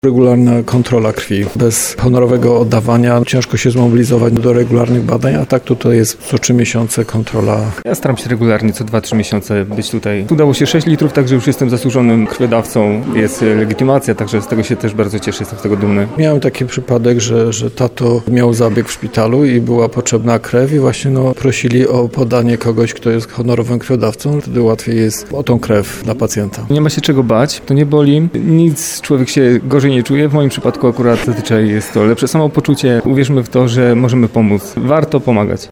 Krople bezcennego daru po raz kolejny popłynęły dzisiaj (01.10.) w Starostwie Powiatowym w Tarnowie.
Dawcy przyznawali, że przyszli, by podzielić się cząstką siebie, z potrzeby serca: